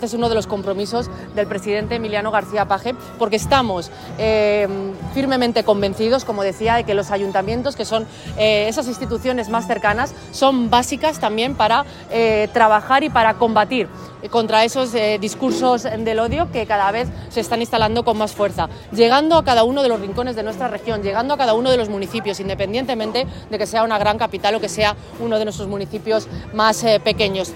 La consejera de Igualdad, Sara Simón
La consejera de Igualdad ha realizado estas declaraciones durante la celebración del desfile-performance “Orgullo y Movimiento” con el que el Gobierno regional sigue conmemorando el Orgulo LGTBI a lo largo del territorio.